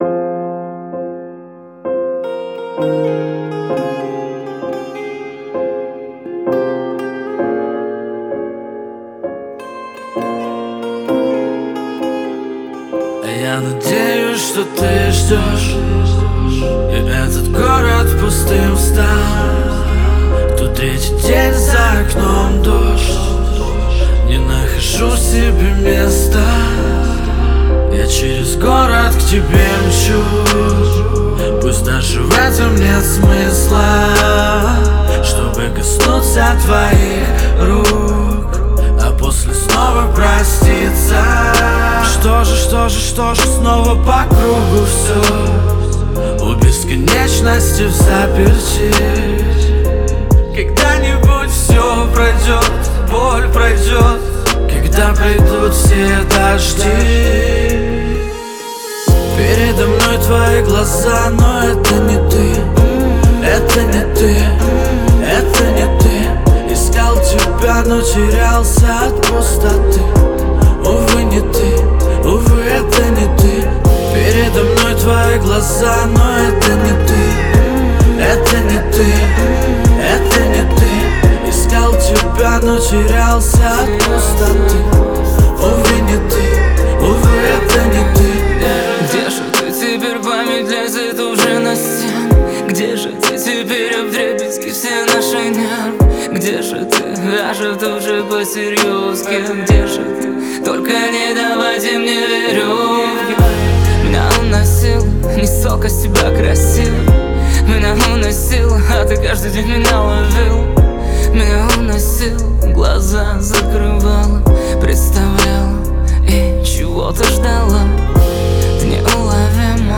трек в жанре поп с элементами электроники